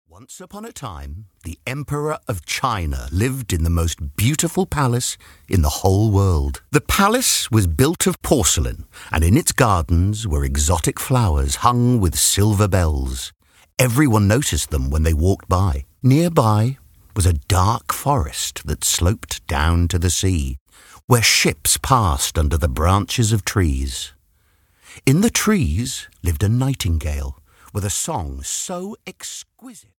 The Nightingale (EN) audiokniha
Ukázka z knihy
• InterpretMichael Ball